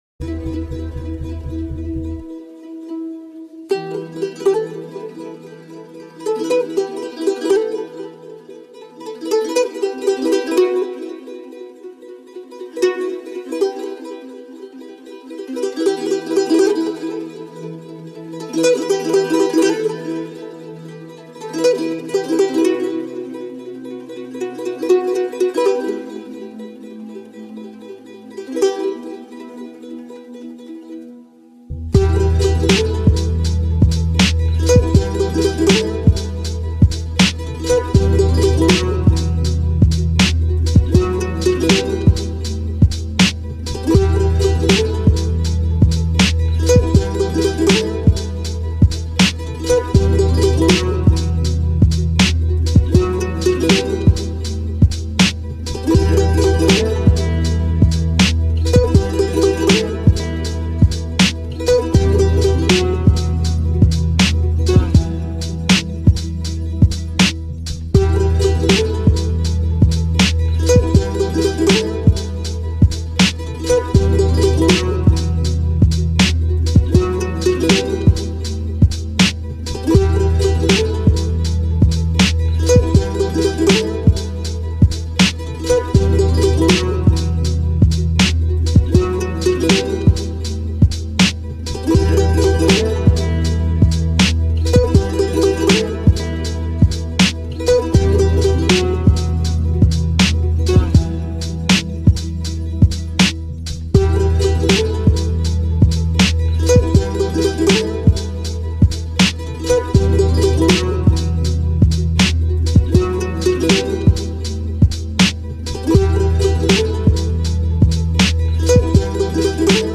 موزیک بی کلام معروف